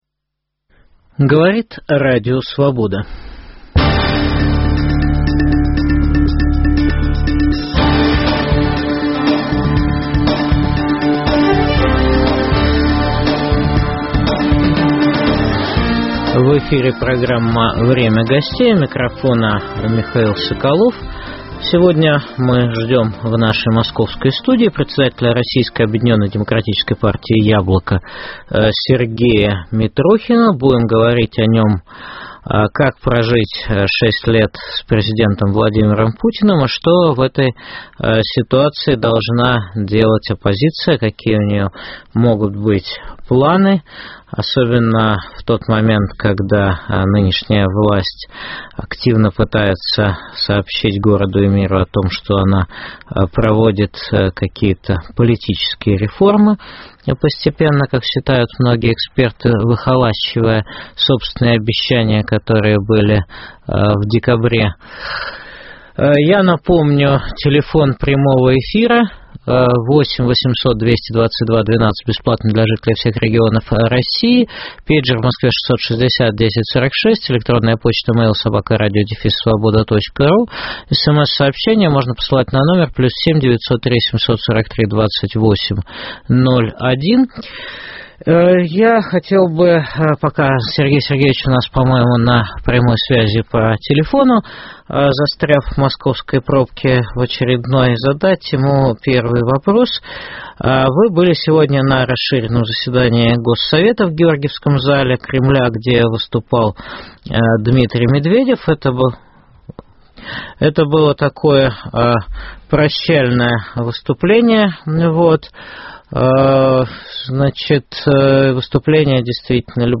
Что должна делать оппозиция? В программе выступит председатель Российской объединенной демократическая партии "Яблоко" Сергей Митрохин.